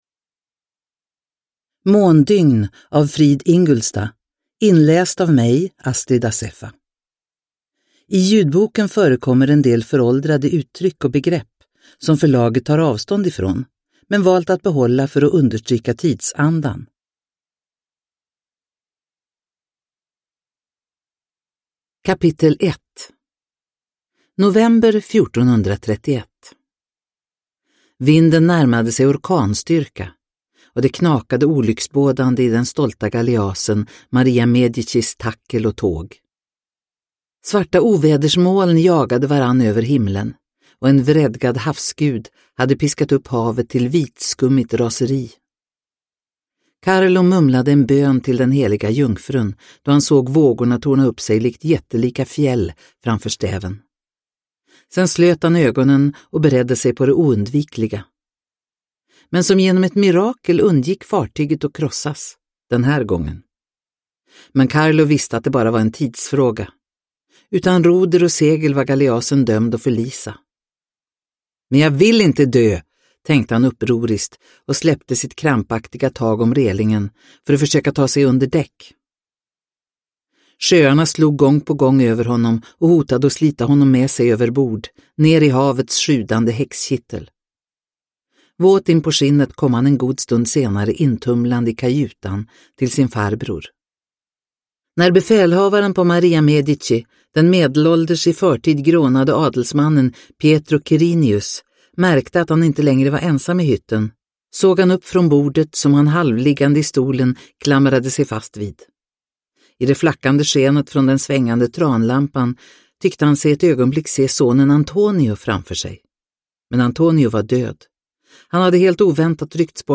Måndygn – Ljudbok – Laddas ner
Uppläsare: Astrid Assefa